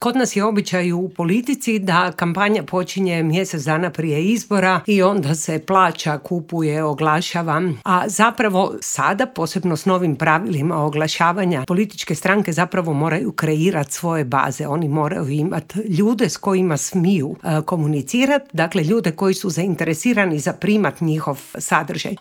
Intervjui Media servisa u tjednu za nama: Od sporta do političkog oglašavanja i glazbe